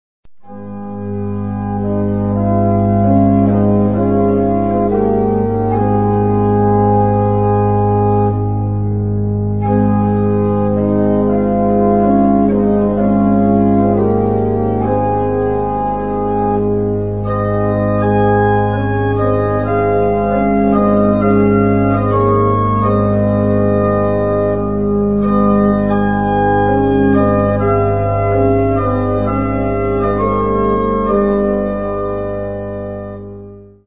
XVII-XVIII), soprattutto per organo, il riferimento alla musica natalizia è evidente, anche se il titolo non contiene un esplicito riferimento al Natale. Notissimo il Capriccio Pastorale per organo di Gerolamo Frescobaldi, nel [Primo libro di] Toccate d'intavolatura di Cembalo et Organo partite da diversa aria e corrente (1637); la suggestione delle melodie natalizie si percepisce sia nella prima parte del brano, più riflessiva